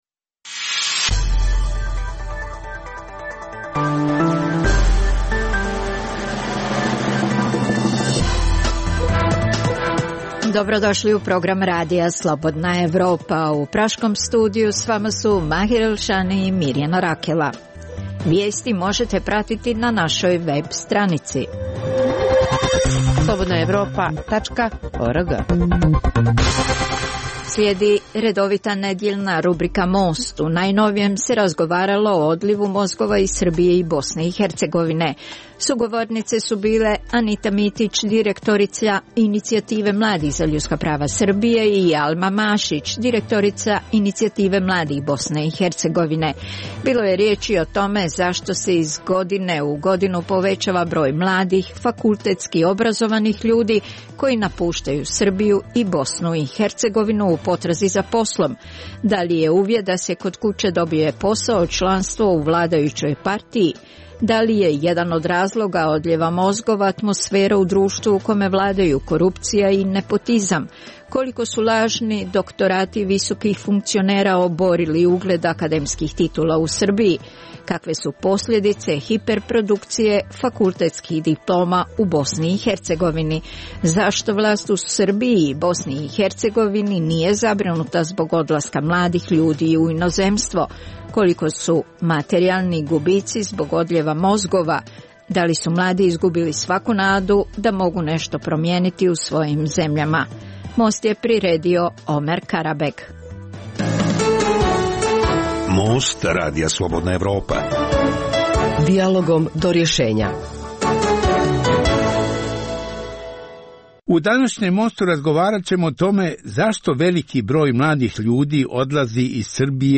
u kojem ugledni sagovornici iz regiona razmtraju aktuelne teme. U najnovijem Mostu razgovaralo se o odlivu mozgova iz Srbije i Bosne i Hercegovine.